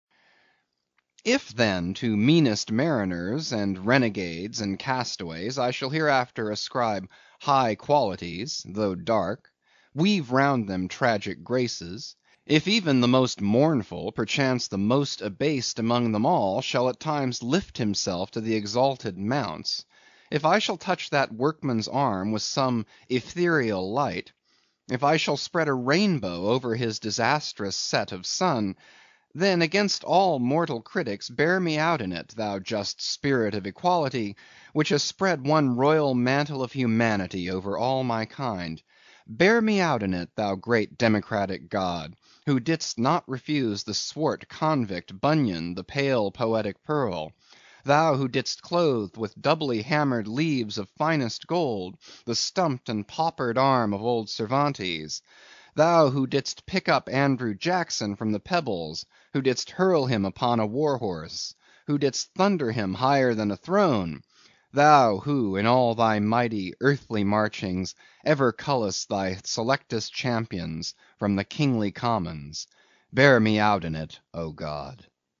英语听书《白鲸记》第346期 听力文件下载—在线英语听力室